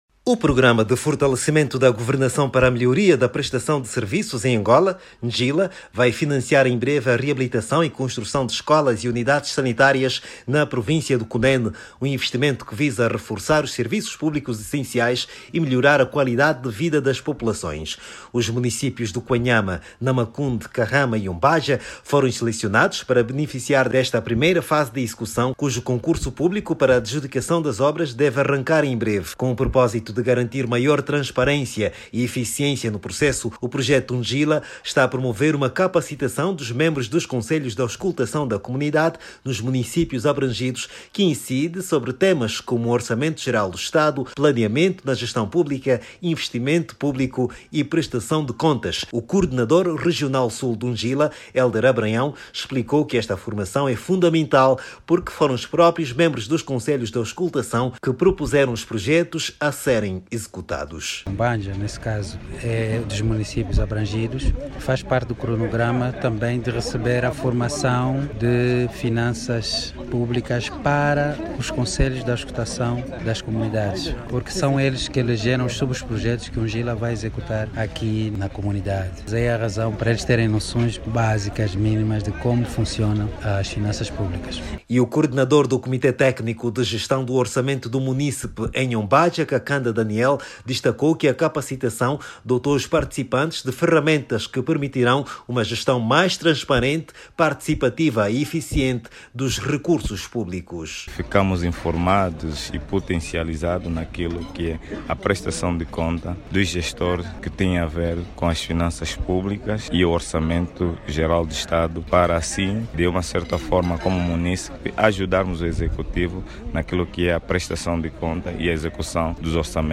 O Programa Njila, financiado pelo Banco Mundial, vai investir na recuperação e construção de infraestruturas sociais na Província do Cunene. O Investimento, visa reforçar os serviços públicos e melhorar a qualidade de vida das populações. Saiba mais dados no áudio abaixo com o repórter